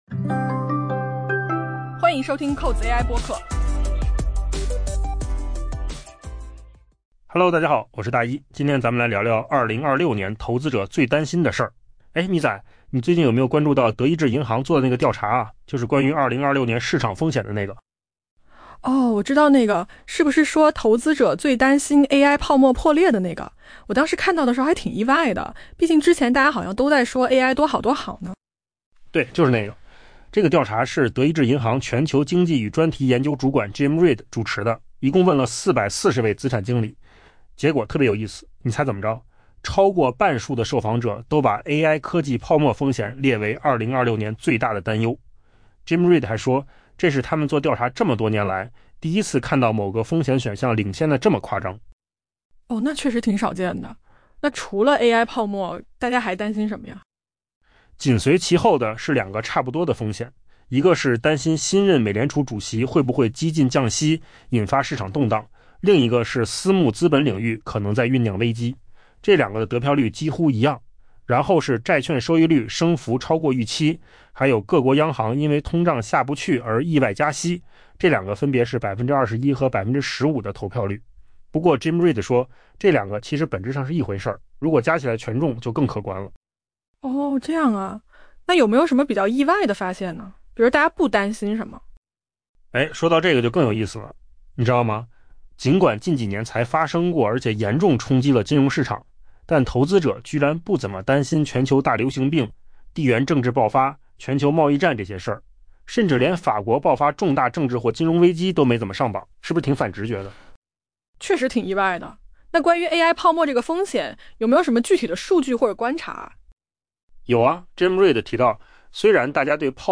AI 播客：换个方式听新闻 下载 mp3 音频由扣子空间生成 德意志银行在上周进行的一项调查，识别了投资者对 2026 年最为担忧的风险。